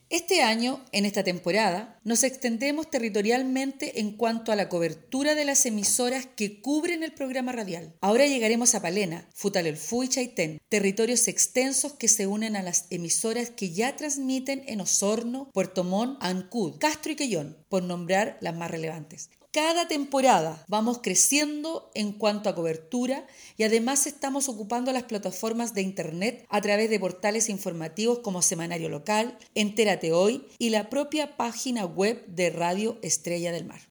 CUÑA-2-SEREMI-DE-GOBIERNO-INGRID-SCHETTINO..mp3